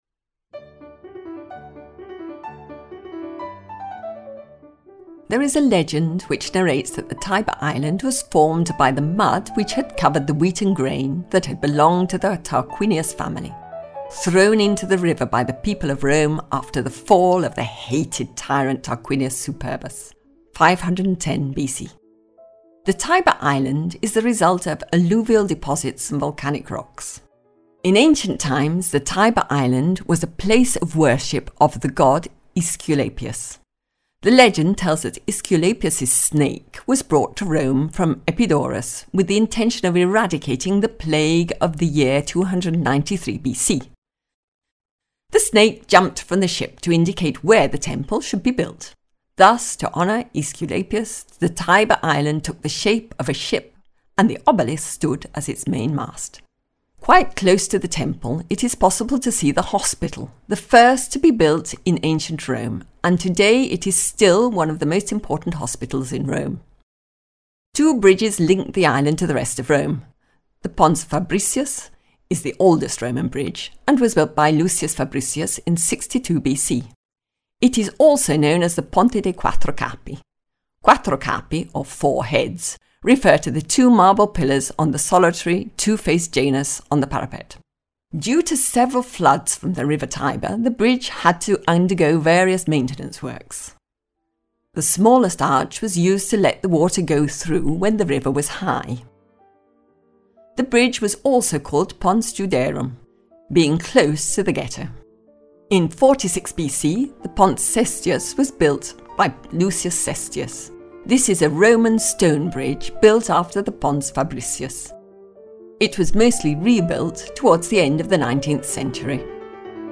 Audio Guide Rome - The Tiber Island - Audiocittà